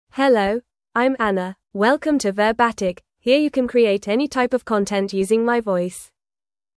FemaleEnglish (United Kingdom)
Anna is a female AI voice for English (United Kingdom).
Voice sample
Anna delivers clear pronunciation with authentic United Kingdom English intonation, making your content sound professionally produced.